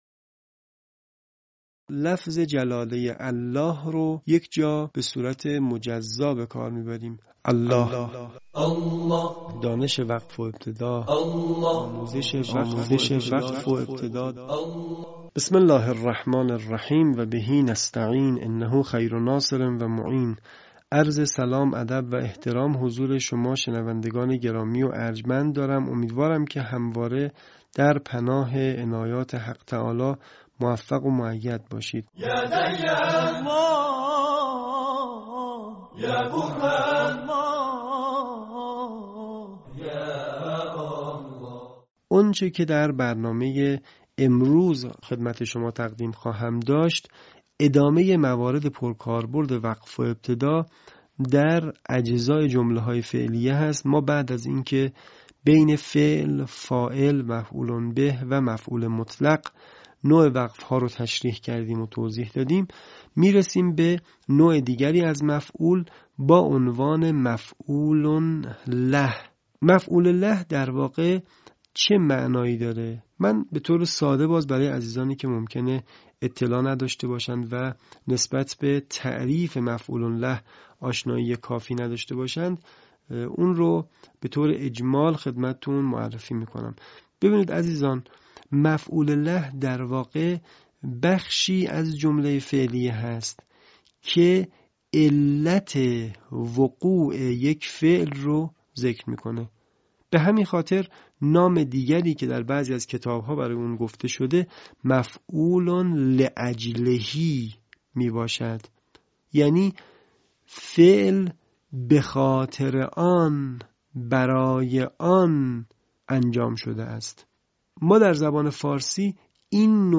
صوت | آموزش وقف و ابتدا در «مفعول‌ٌ له»
به همین منظور مجموعه آموزشی شنیداری(صوتی) قرآنی را گردآوری و برای علاقه‌مندان بازنشر می‌کند.